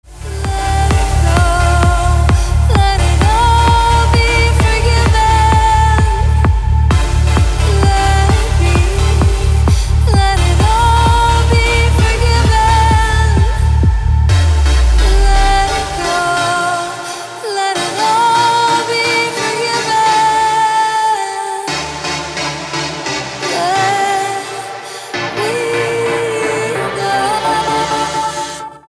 • Качество: 320, Stereo
громкие
женский вокал
dance
Electronic
электронная музыка
club
electro house